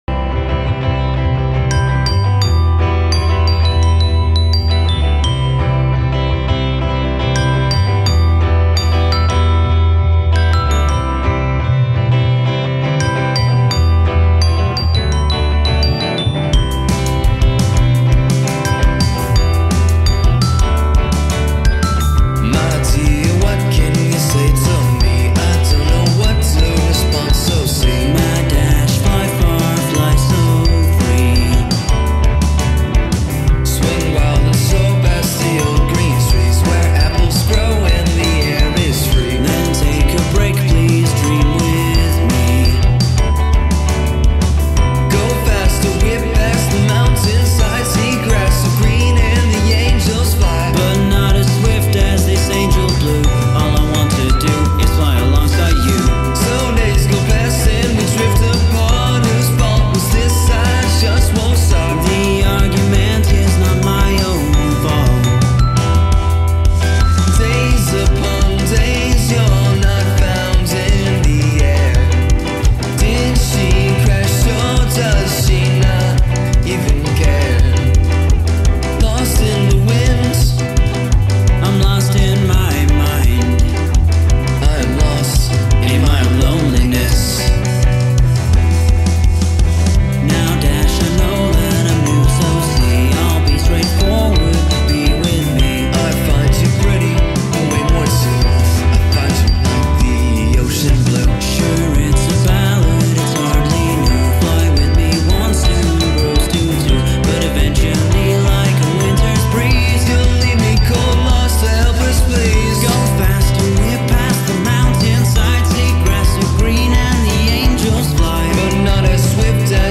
A nice little rainbow dash song/ballad/thing
(more indie/rock)